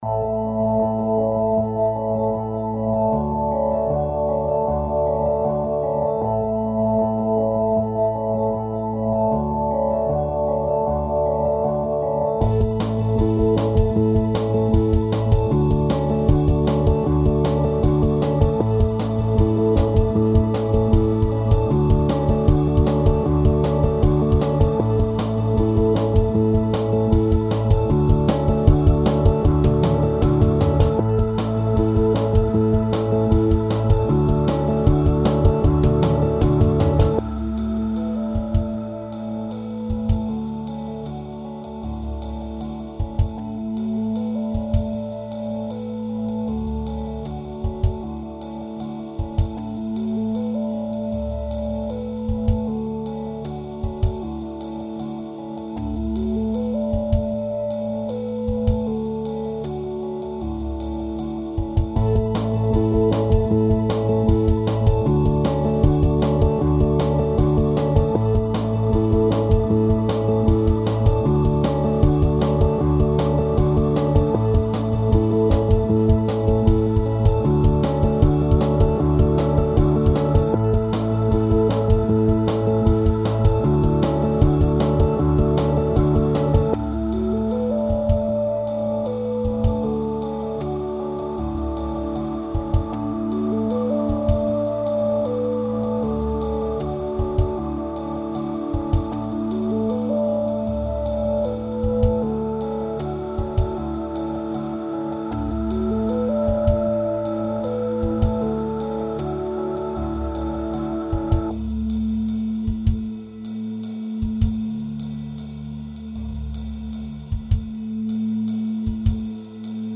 electronic duo